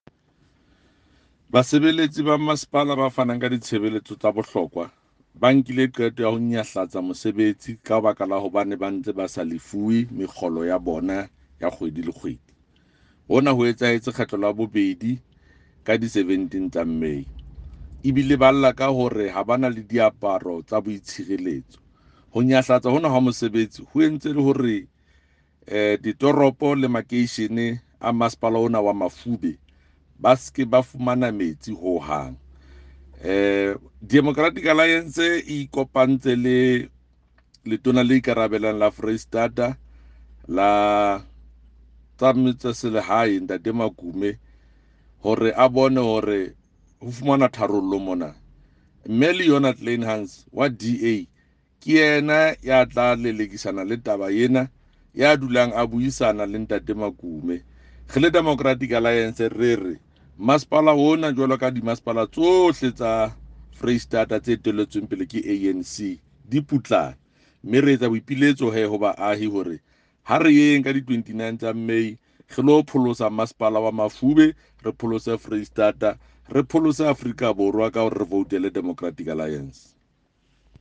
Sesotho by Jafta Mokoena MPL.